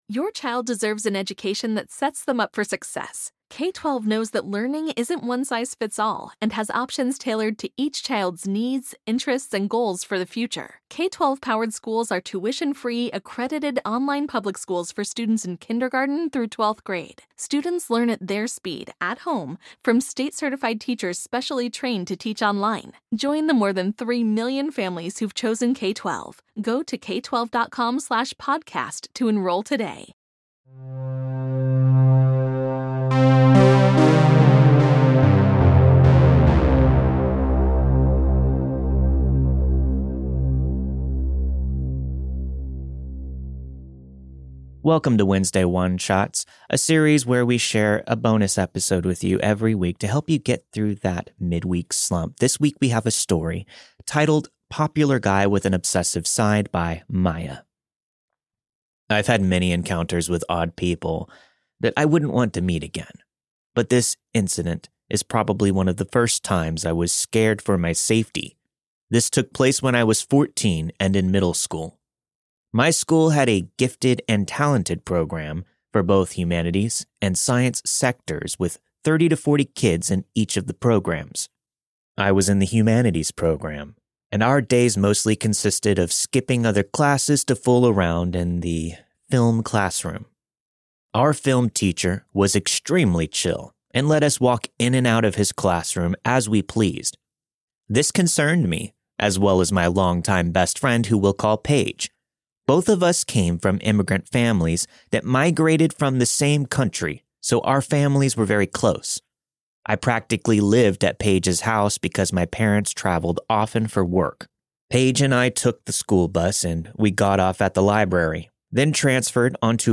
The story you've heard this week was narrated and produced with the permission of its respective author.